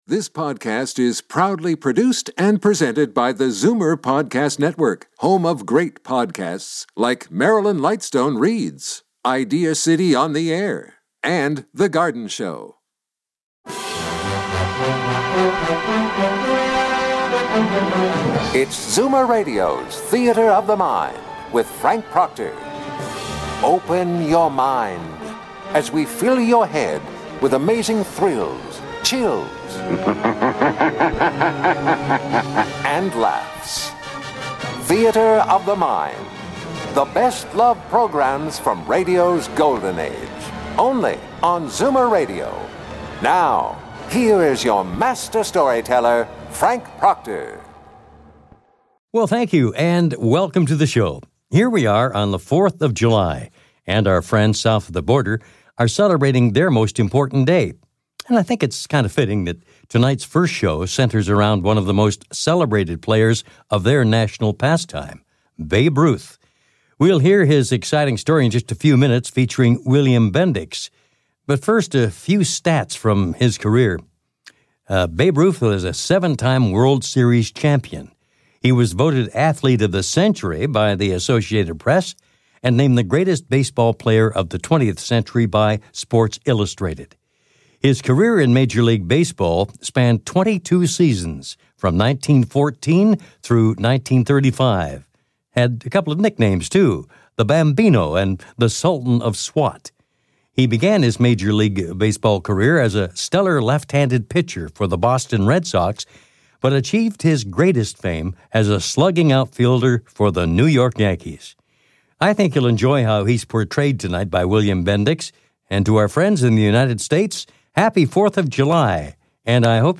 A half-hour of drama, mystery or suspense is followed by a half-hour of comedy.
Dramatized Multigenre Anthology